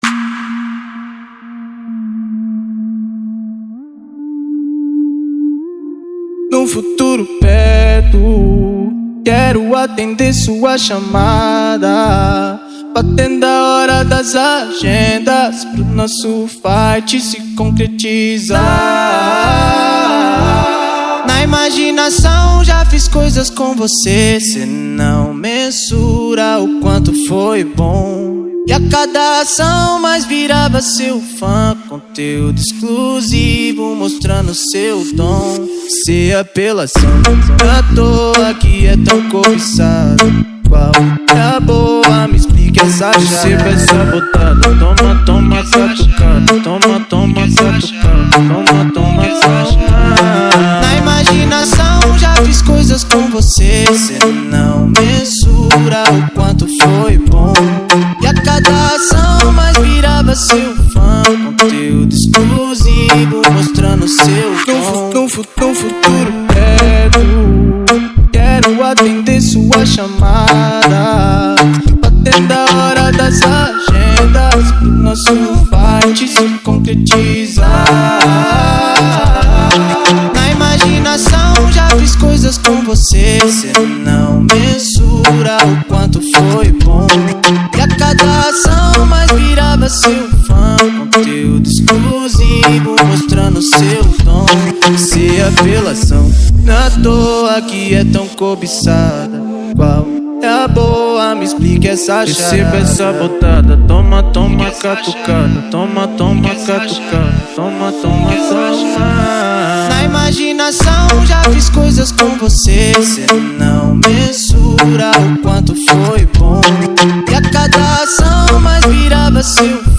2024-09-29 20:18:52 Gênero: Funk Views